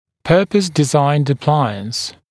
[‘pɜːpəs dɪ’zaɪnd ə’plaɪəns][‘пё:пэс ди’зайнд э’плайэнс]аппарат, разработанный для определенной цели